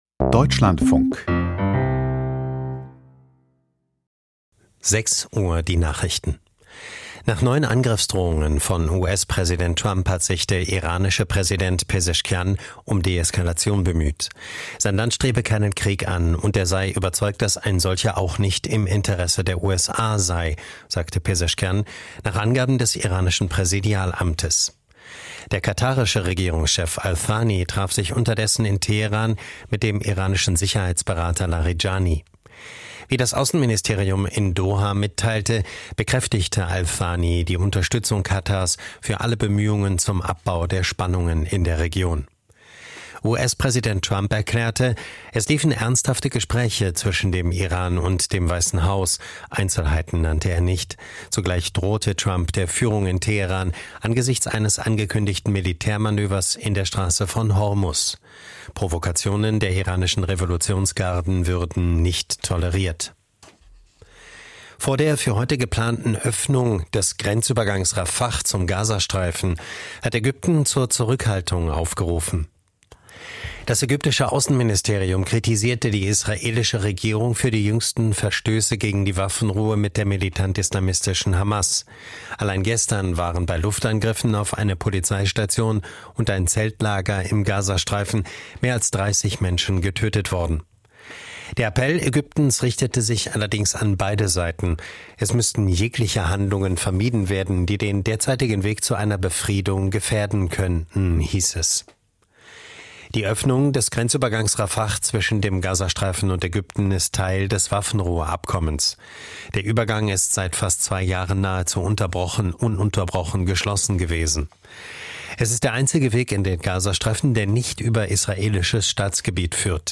Die Nachrichten vom 01.02.2026, 06:00 Uhr